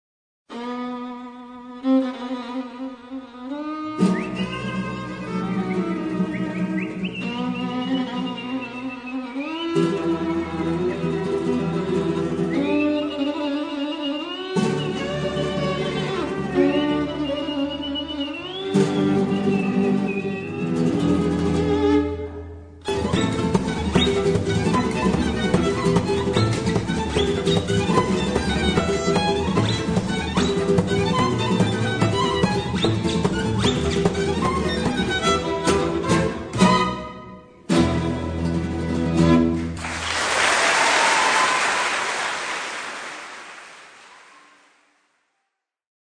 violon - accordéon - chant/guitare/balalaïka